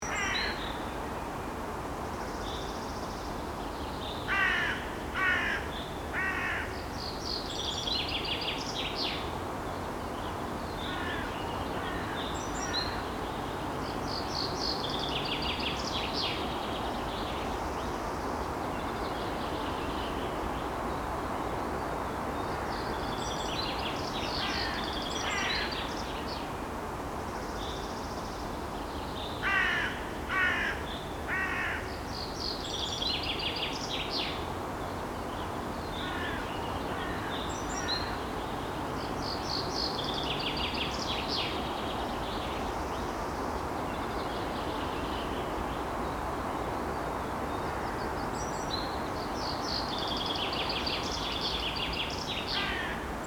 Download Free Park Sound Effects | Gfx Sounds
Park-playground-side-street-birds-chirping-loop.mp3